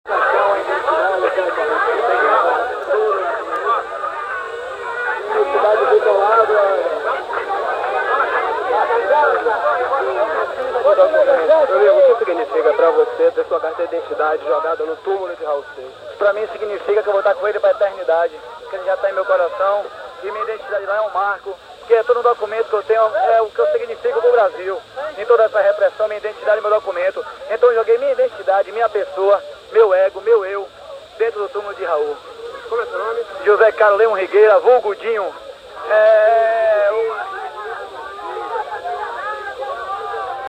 Era necessário produzir conteúdo até o sábado e lá fomos nós para o sepultamento de Raul no Jardim da Saudade.
Gravei toda a sonoridade da multidão de fãs carregando o caixão, cantando as músicas e gritando “Raul, Raul…”.